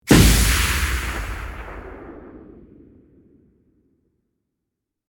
Rocket Launcher Single Shot 3 Sound Effect Download | Gfx Sounds
Rocket-launcher-single-shot-3.mp3